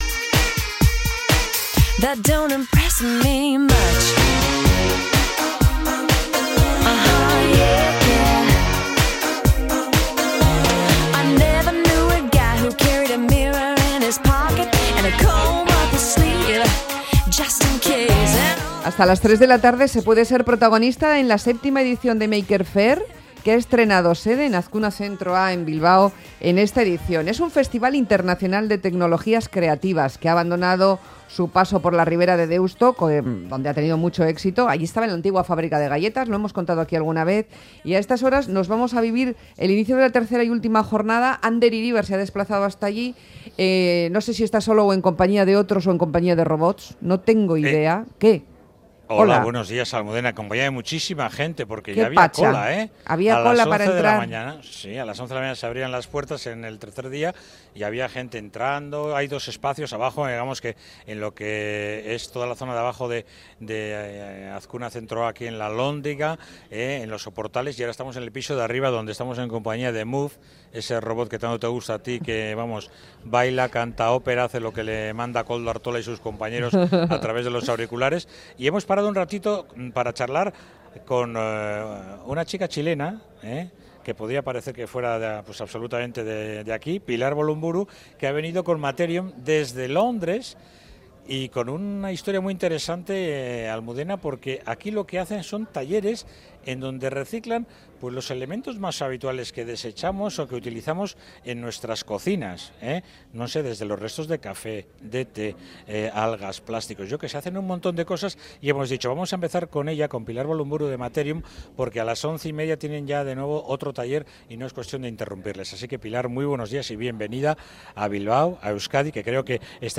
Séptima Bilbao Maker Faire desde Azkuna Zentroa